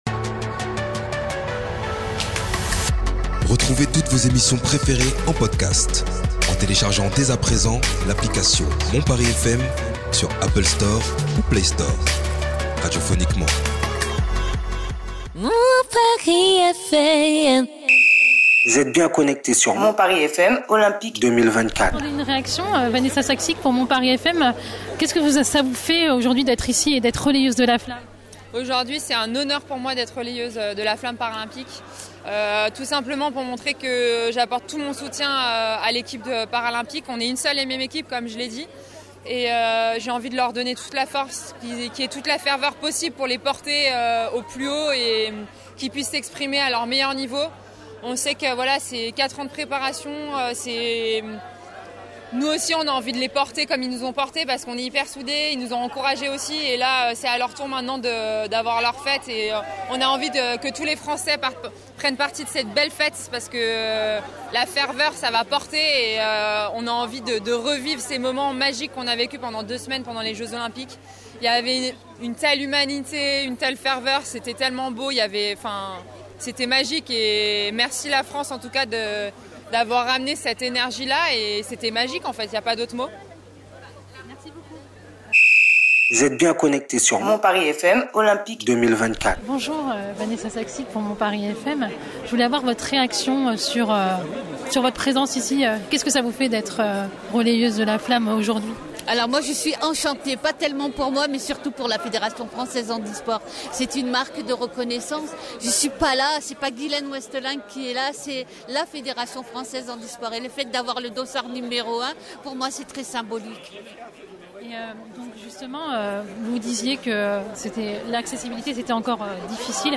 Interview réalisée le 2 Aout 2024